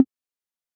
tap1.ogg